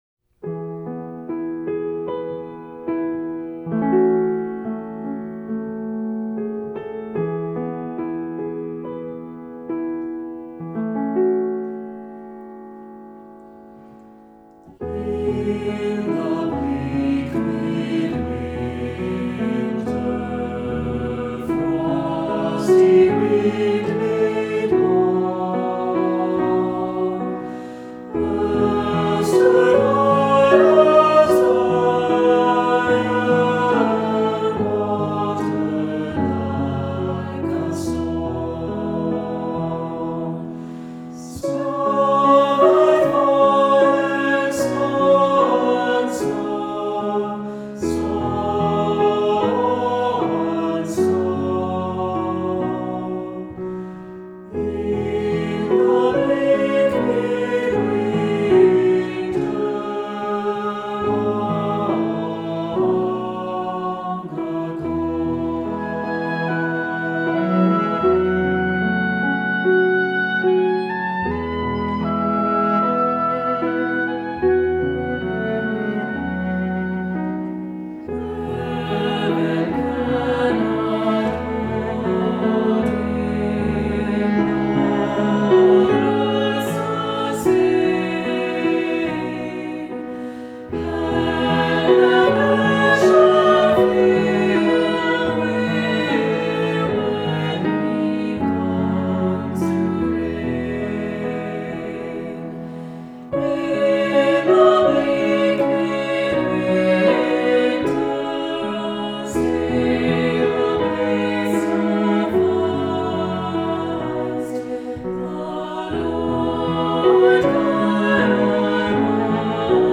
Voicing: SATB,Soloist or Soloists